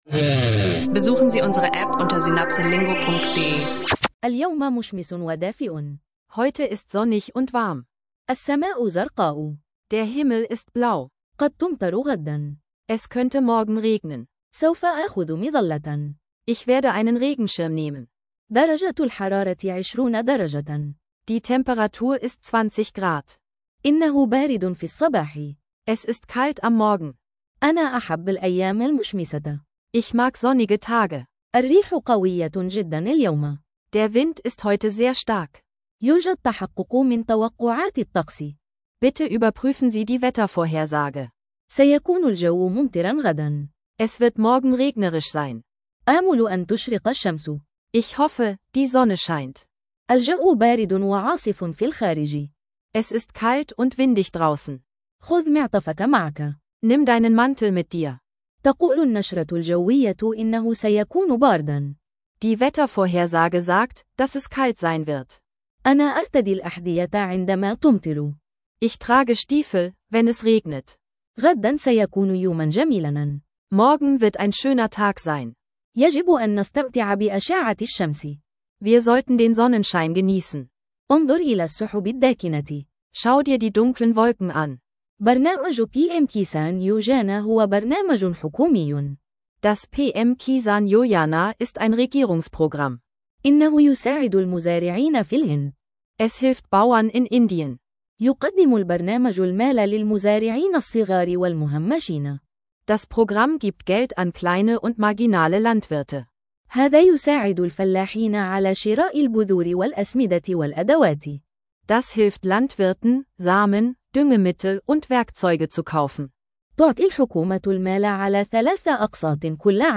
In dieser Episode des SynapseLingo Podcasts entdecken Sie, wie Sie Arabisch lernen mit praktischen Gesprächen über das tägliche Wetter und grundlegende Vorhersagen. Ideal für Anfänger, die Arabisch lernen mit Spaß und interaktiven Audiolektionen möchten.